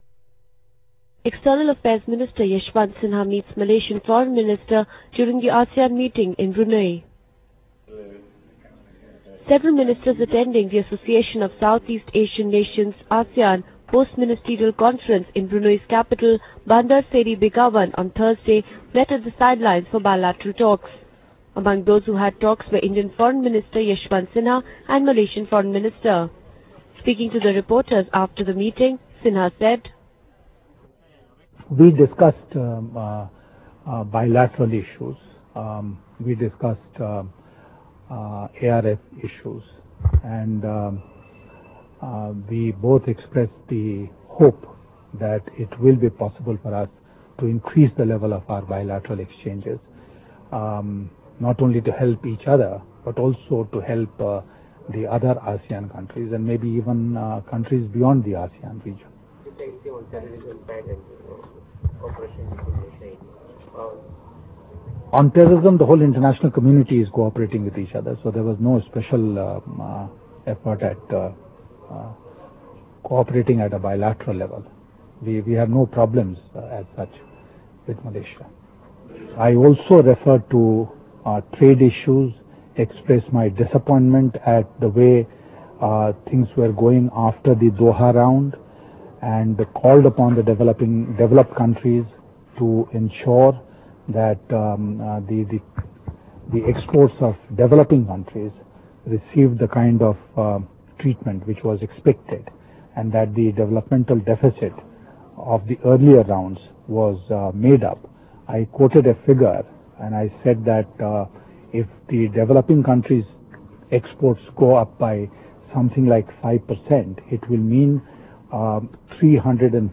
US Secretary of State Colin Powell (R) shares a light moment with Indian Foreign Minister Yashwant Sinha (L) and China's Foreign Minister Tang Jiaxuan during an audience with Brunei's Sultan Hassanal Bolkiah at the palace in Bandar Seri Begawan, on Thursday.